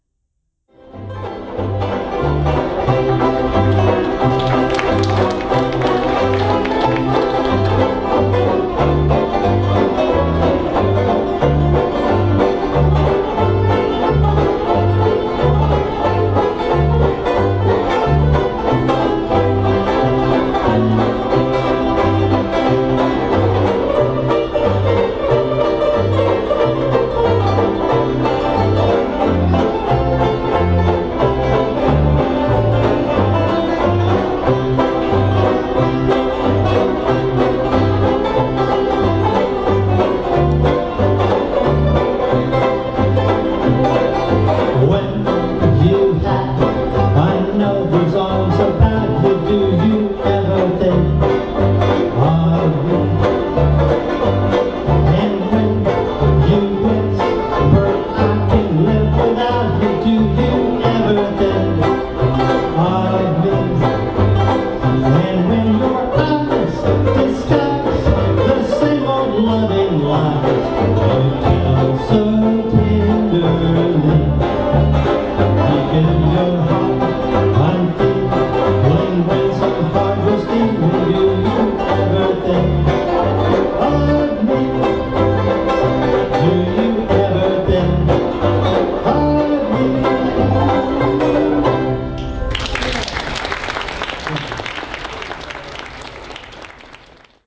Listen to the WineLand Banjo Band perform "Do You Ever Think Of Me?" (mp3)
Vocal